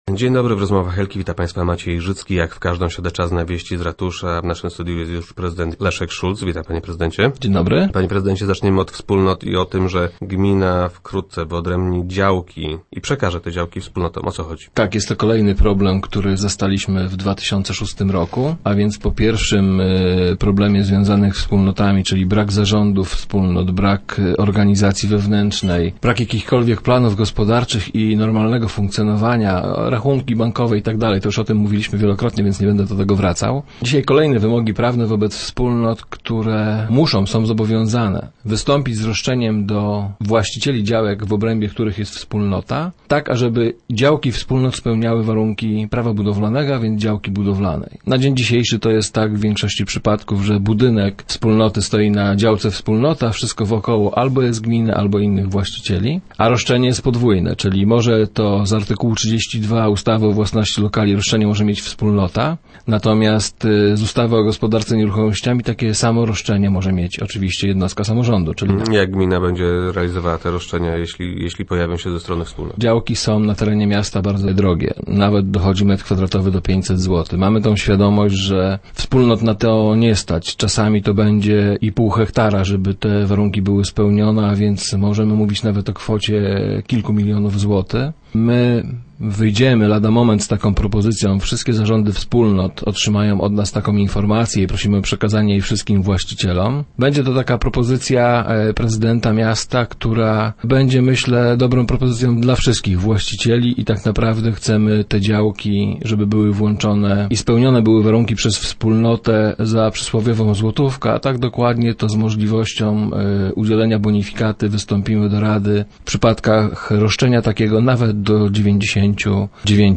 Być może swoje siedziby znajdą w nim organizacje pozarządowe. - Mamy pomysły na wykorzystanie dworcowych pomieszczeń - twierdzi Leszek Szulc, zastępca prezydenta Głogowa, który był gościem Rozmów Elki.